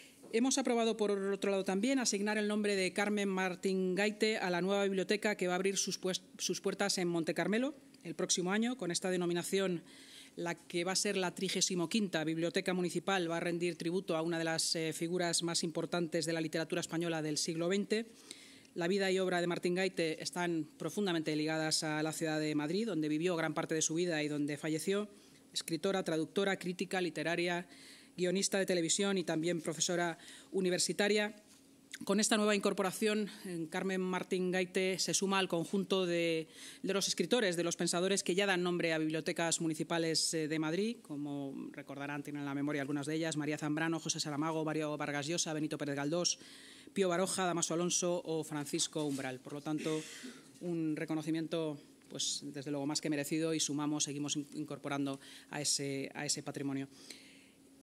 Nueva ventana:La vicealcaldesa y portavoz municipal, Inma Sanz